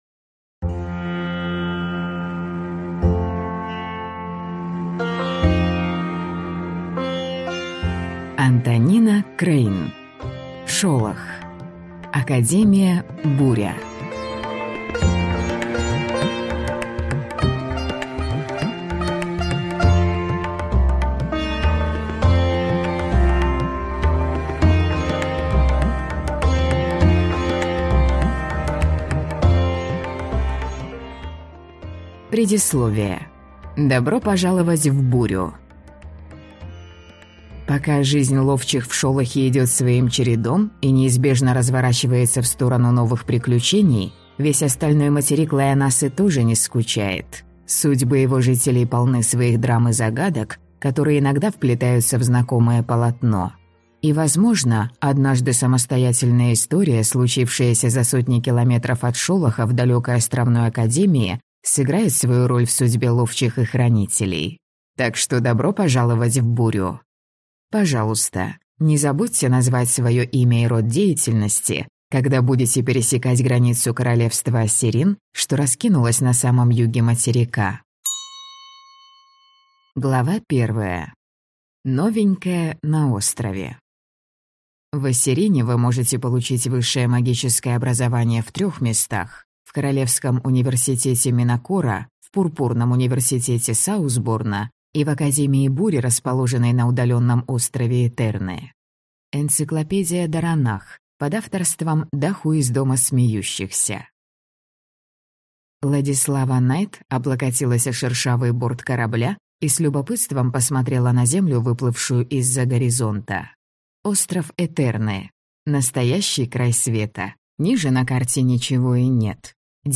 Аудиокнига Шолох. Академия Буря | Библиотека аудиокниг